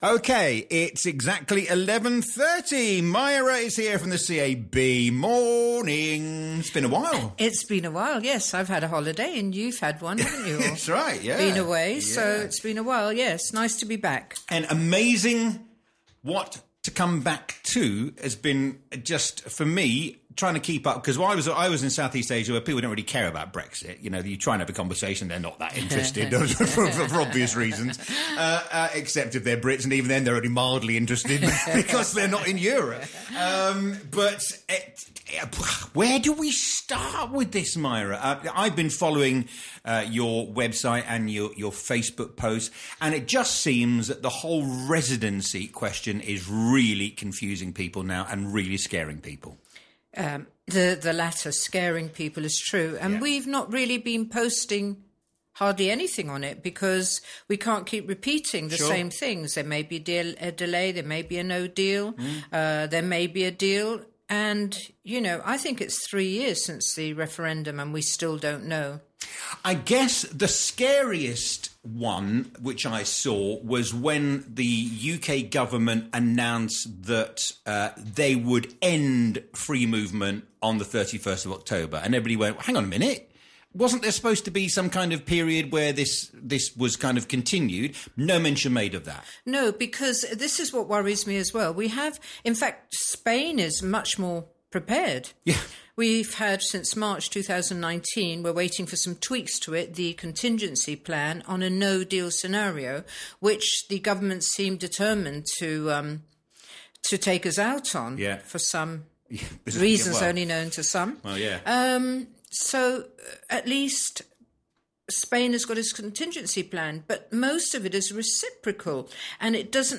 Residency Applications-What is the Present Status? Live on Talk Radio Europe
Residency applications, updating for permanent residency, relocation, how to apply and can you apply? This topic will be discussed by a CAB senior adviser on Friday September 13 at 11.30am.